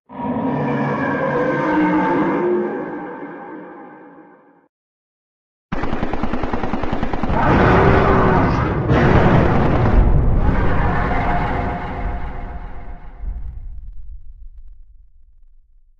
Roar.ogg